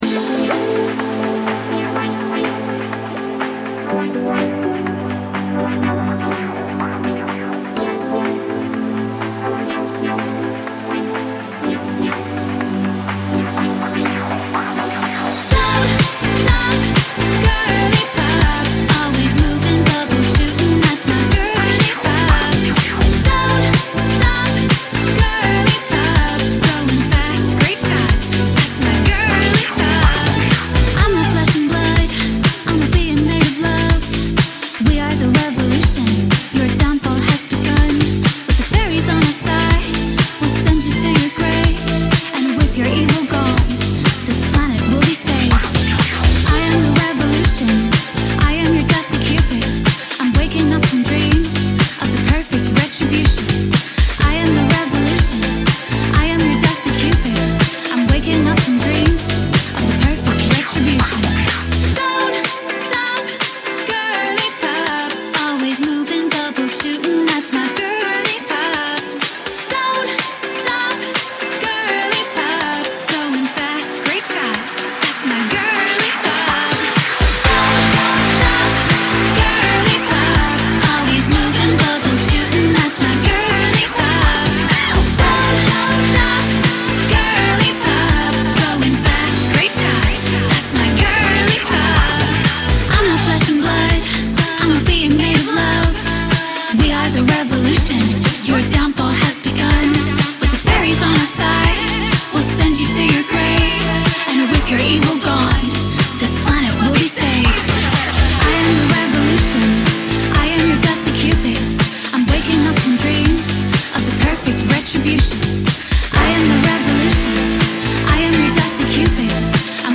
BPM124-124
Audio QualityPerfect (High Quality)
Full Length Song (not arcade length cut)